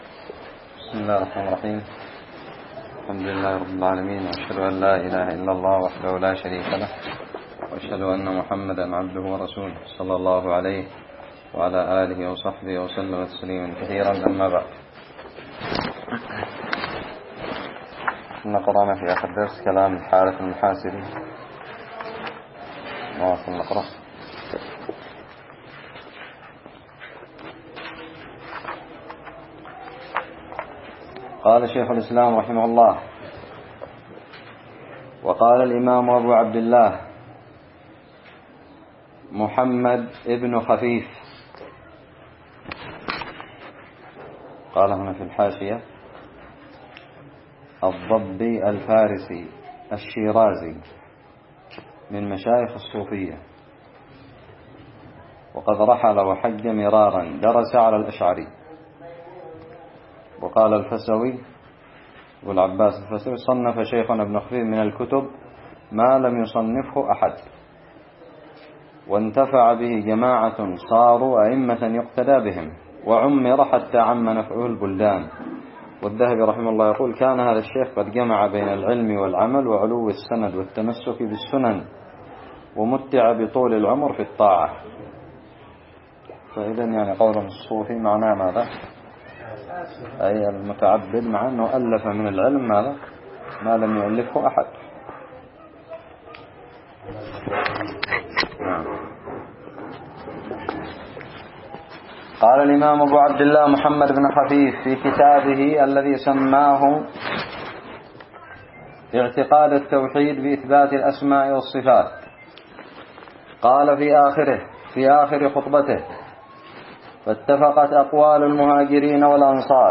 الدرس الرابع عشر من شرح متن الحموية
ألقيت بدار الحديث السلفية للعلوم الشرعية بالضالع